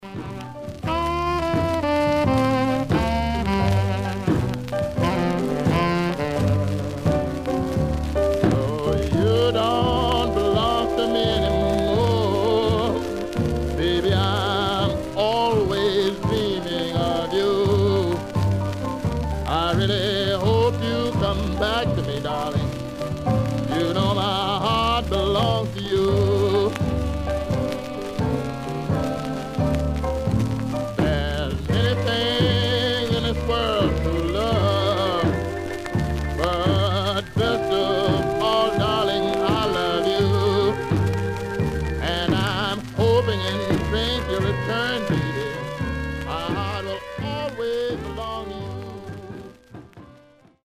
Surface noise/wear Stereo/mono Mono
Rythm and Blues